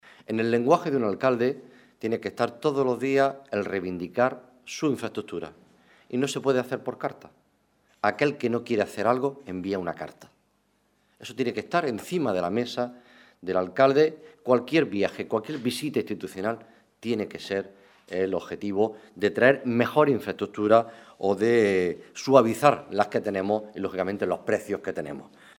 Rueda de prensa ofrecida por el candidato del PSOE a la Alcaldía de Almería, Juan Carlos Pérez Navas, sobre turismo